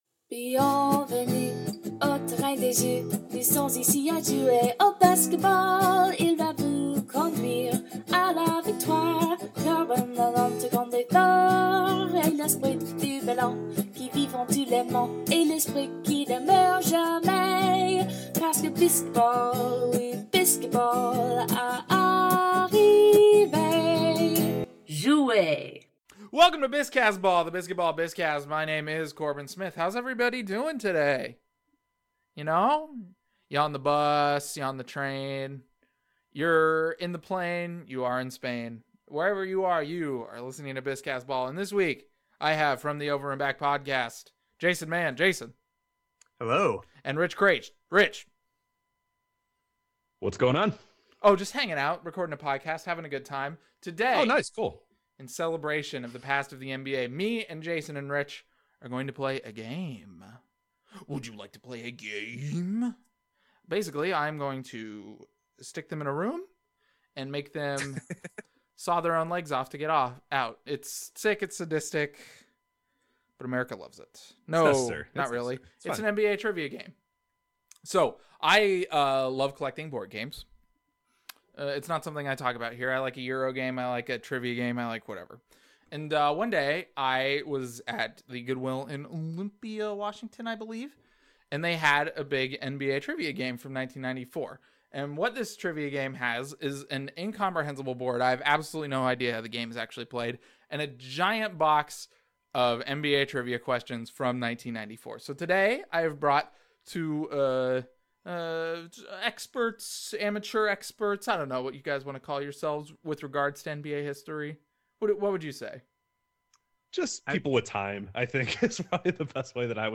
Features him seeming lost and despondent in his task!